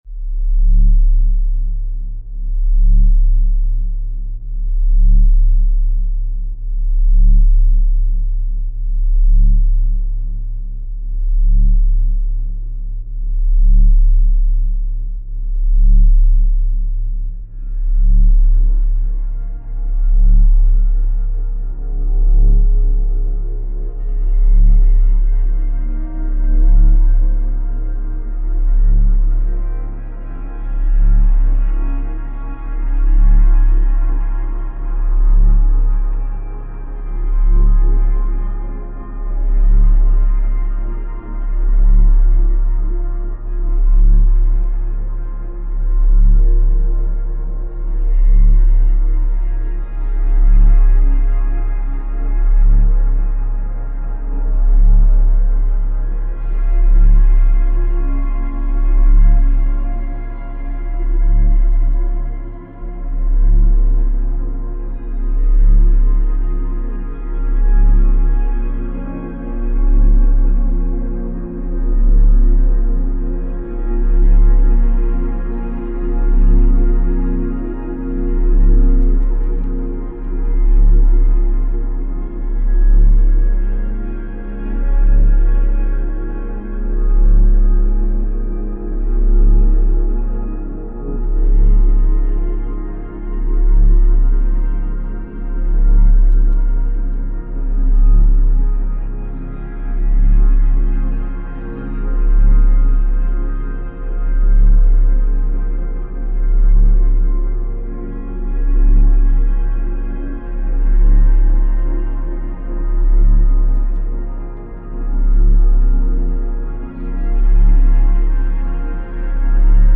some ambienty thing: